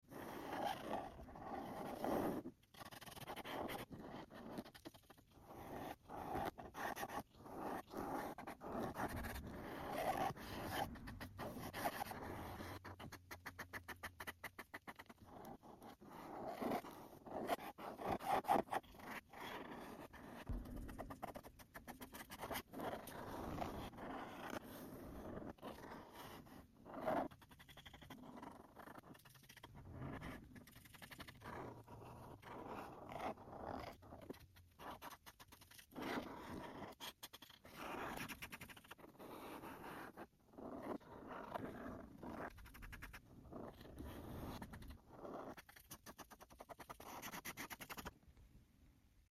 ASMR Acrylic Marker Coloring sound